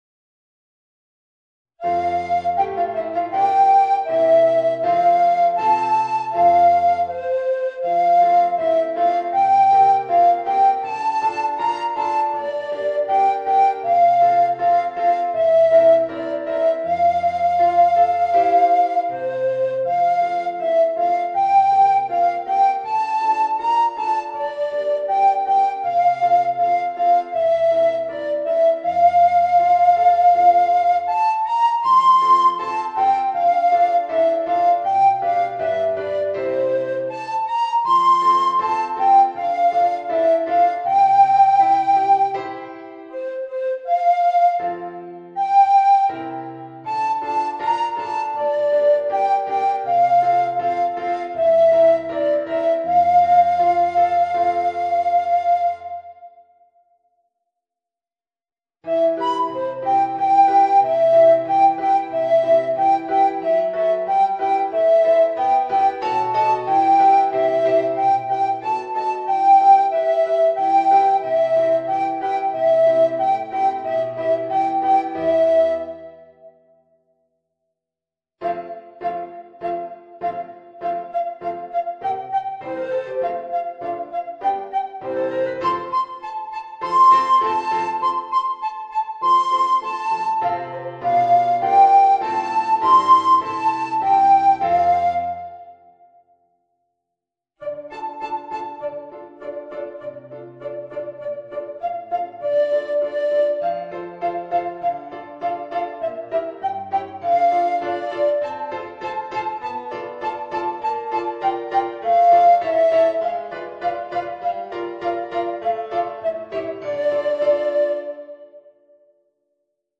Alto Recorder